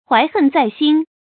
懷恨在心 注音： ㄏㄨㄞˊ ㄏㄣˋ ㄗㄞˋ ㄒㄧㄣ 讀音讀法： 意思解釋： 把怨恨藏在心里。